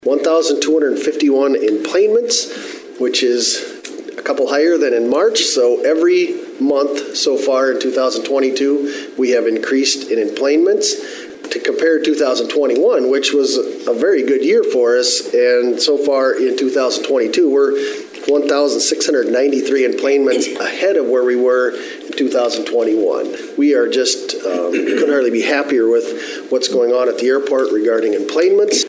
City commissioner Jamie Huizenga says traffic has been steadily increasing.